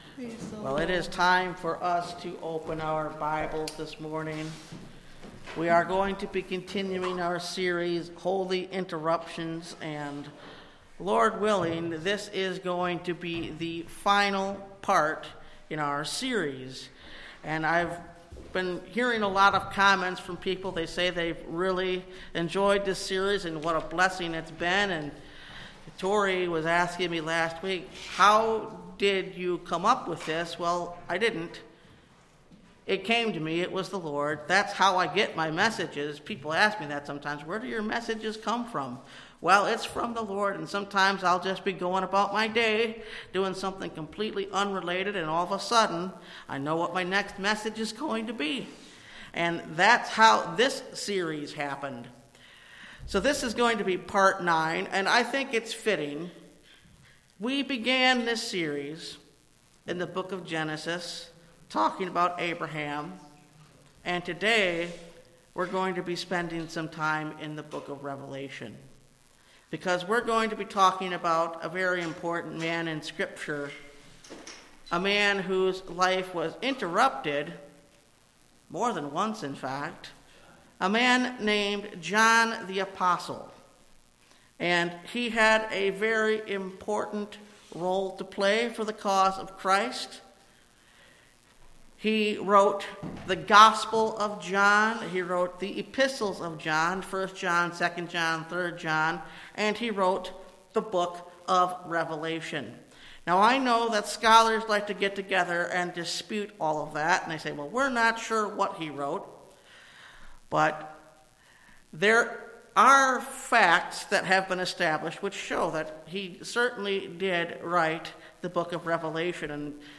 Holy Interruptions – Part 9 (Message Audio) – Last Trumpet Ministries – Truth Tabernacle – Sermon Library